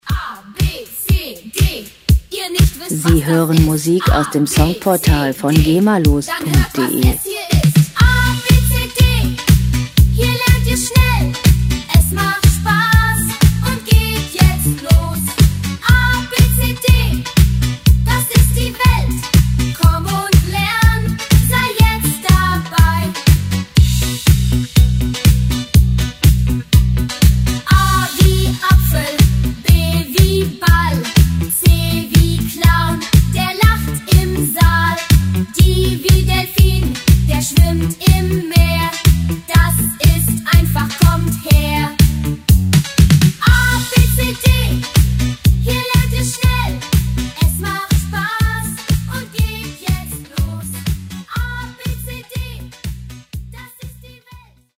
Gema-freie Kinderlieder
Musikstil: Disco
Tempo: 122 bpm
Tonart: H-Moll
Charakter: motivierend, lehrreich
Instrumentierung: Kindergesang, E-Bass, E-Gitarre, Keyboard